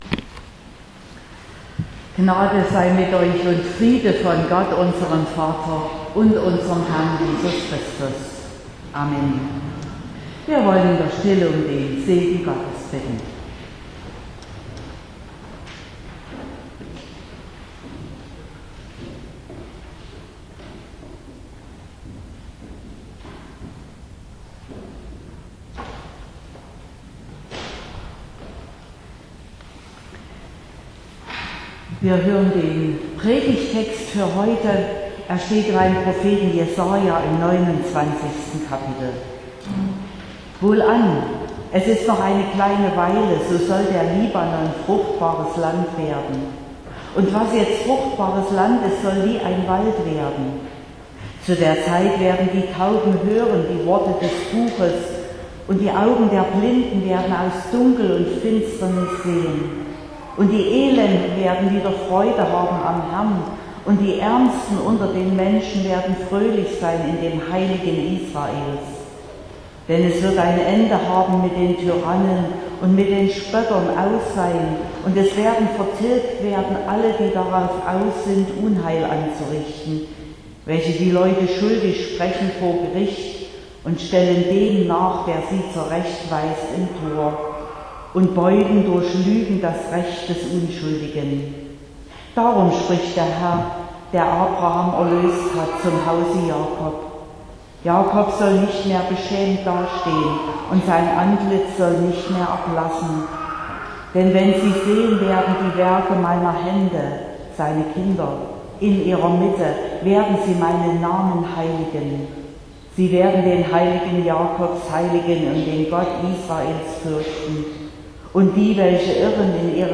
Predigt und Aufzeichnungen
Predigt (Audio): 2023-08-27_Tragt_Gottes_Wort_weiter.mp3 (22,3 MB)